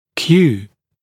[kjuː][кйу:]сигнал, стимул, раздражитель